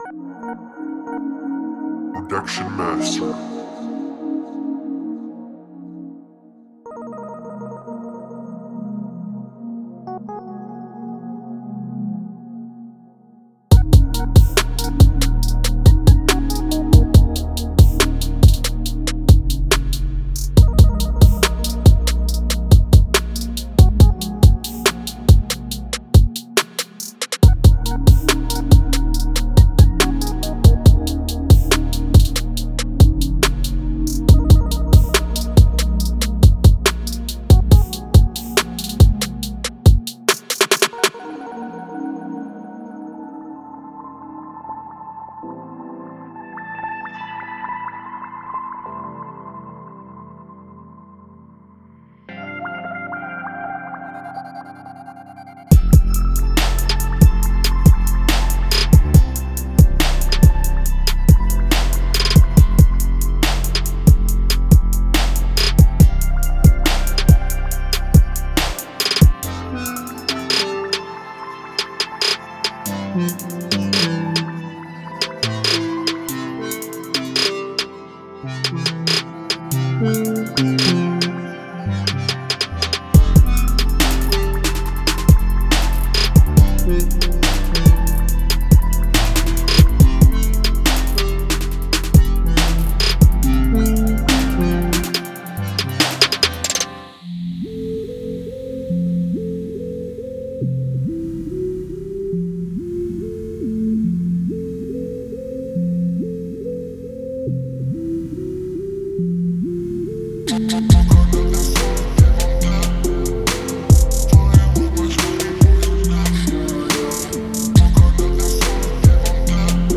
• 22 Bass Loops
• 67 Melodic Loops
• 8 Vocal Loops